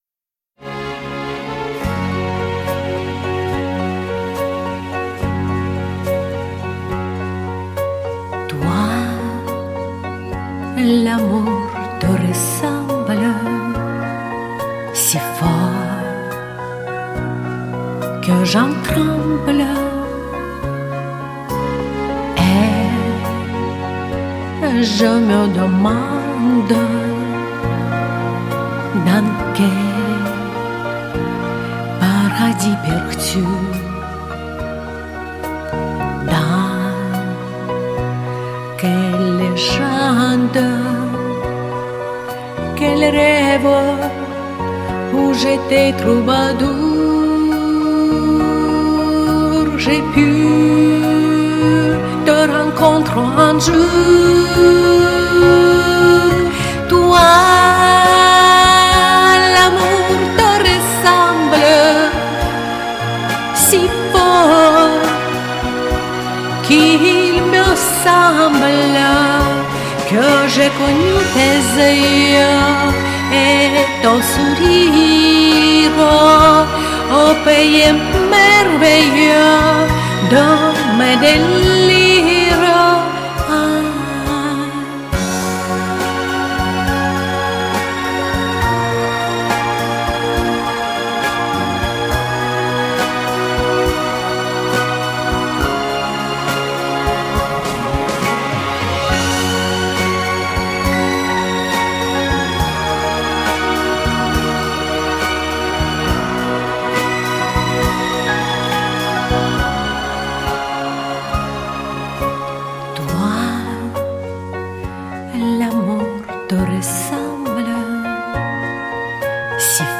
то есть дальше уже шарм пропал,...и началось "горло"